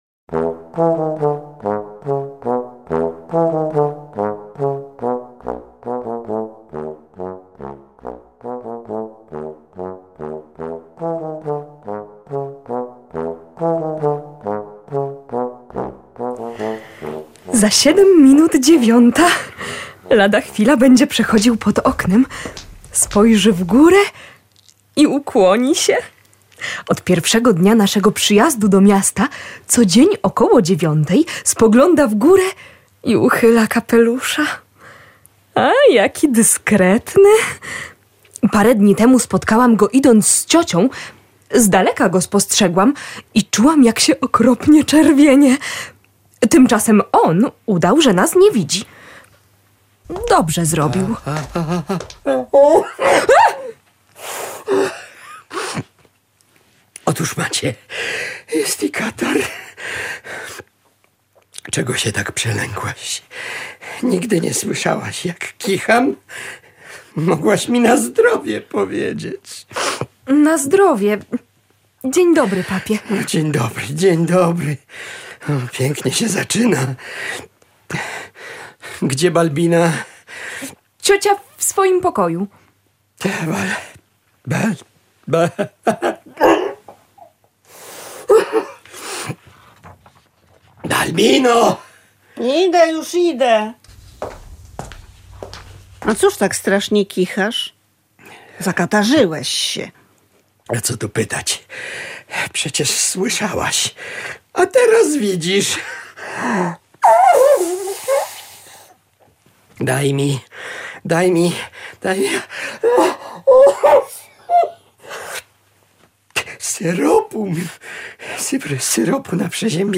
Słuchowisko na podstawie komedii Aleksandra Fredry o znikających kaloszach…